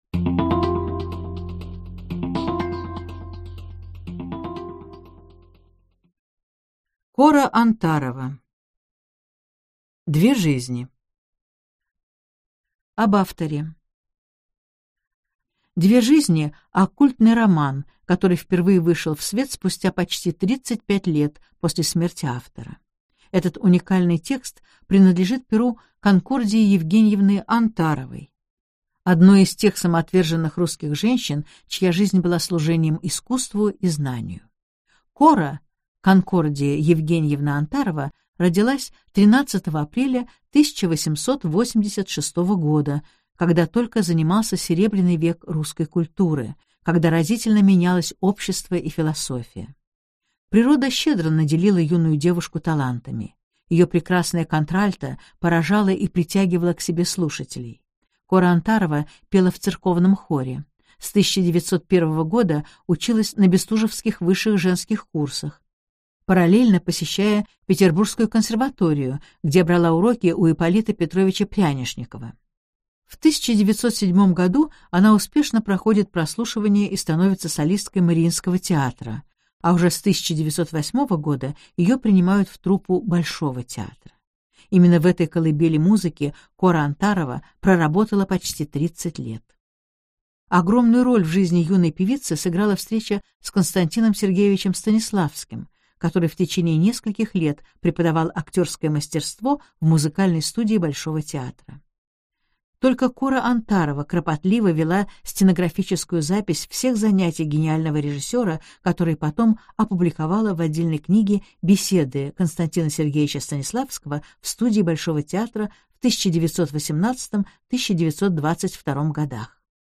Аудиокнига Две жизни. I-II части | Библиотека аудиокниг